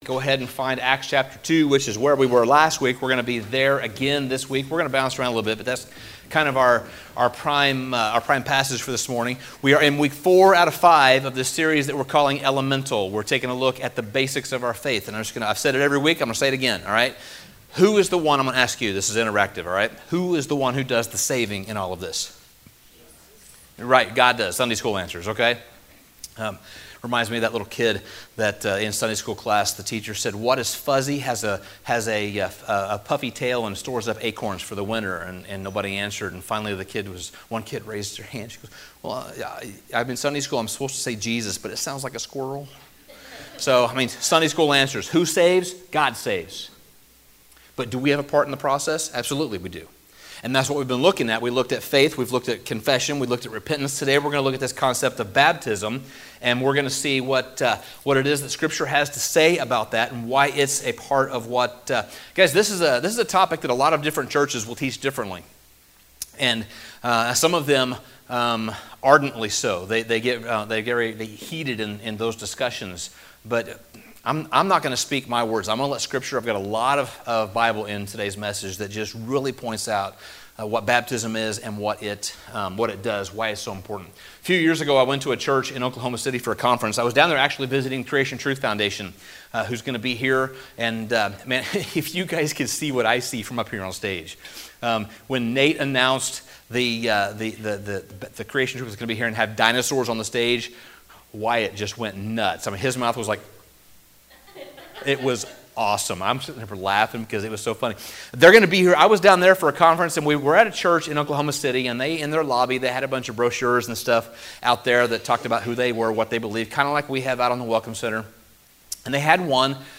Sermon Summary The fourth element is one that many people are confused about. Baptism is the fourth element, and it is one that we need to examine carefully to see just what the Bible has to say.